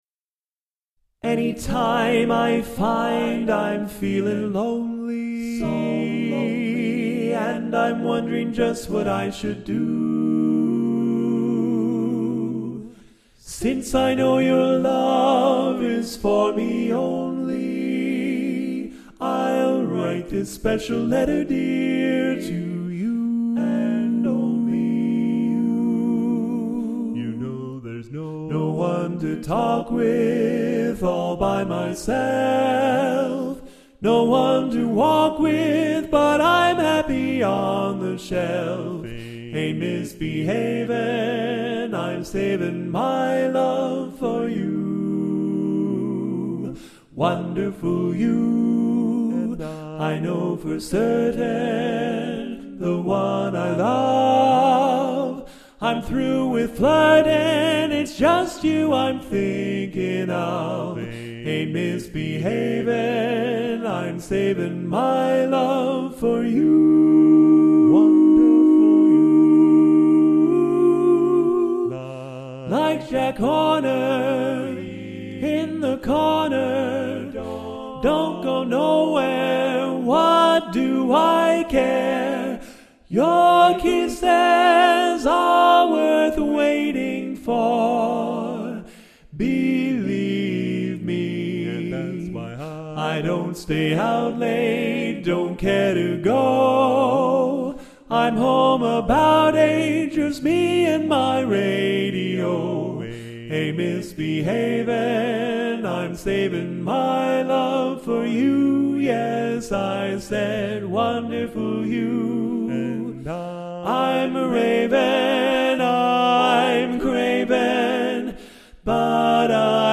Genre: A Cappella.